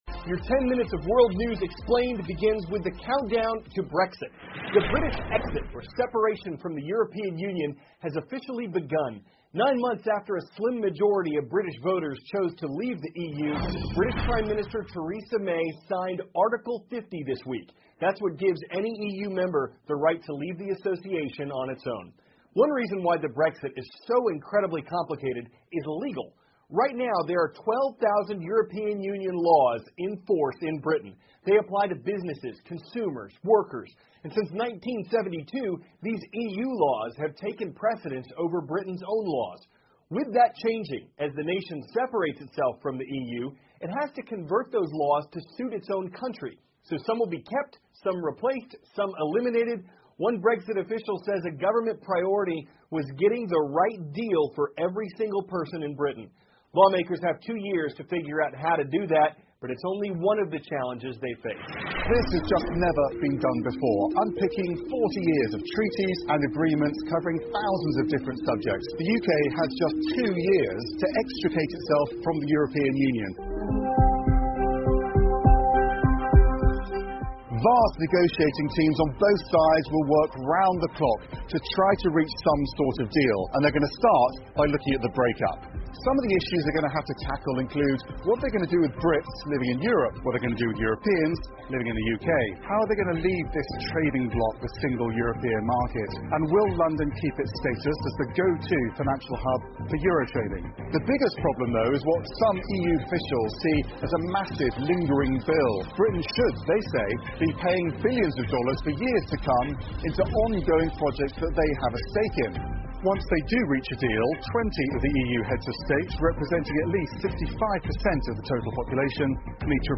美国有线新闻 CNN 历时九个月 英国正式启动脱欧程序 听力文件下载—在线英语听力室